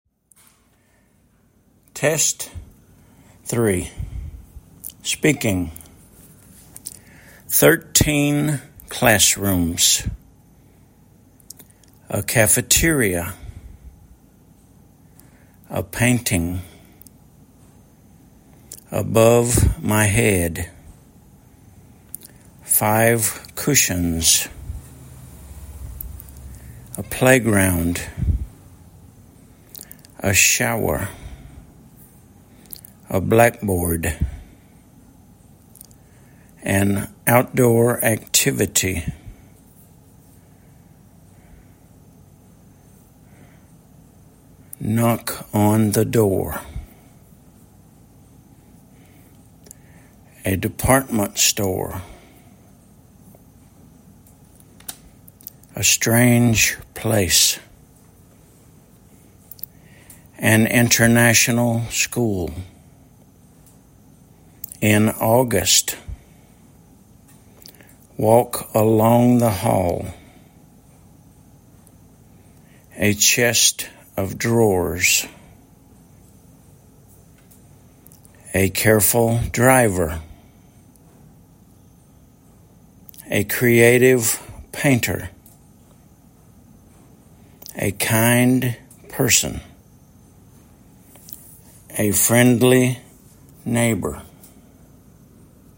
thirteen classrooms /ˈθɜrˌtin ˈklæsˌrumz/
a cafeteria   /ˌkæfəˈtɪriə/
five cushions  /faɪv ˈkʊʃənz/
knock the door  /nɑk  ðə  dɔr/
a chest of drawers   /ʧɛst   əv   drɔrz/
a friendly neighbor   /ˈfrɛndli   ˈneɪbər/